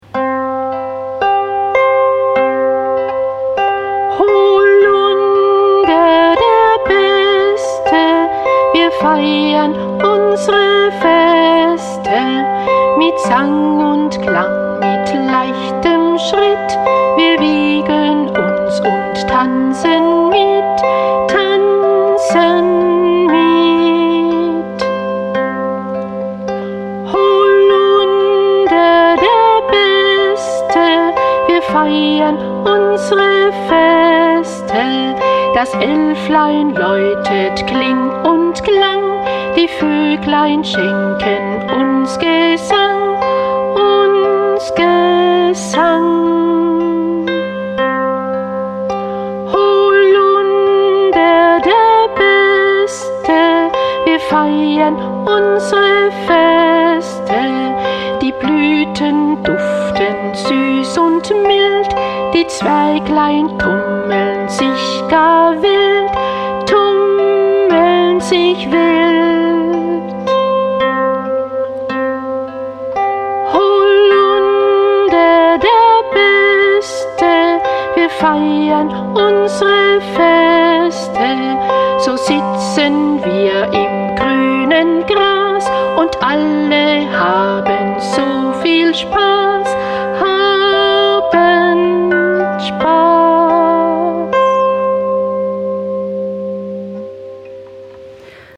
Ein kleines musikalisches Märchen für Kinder.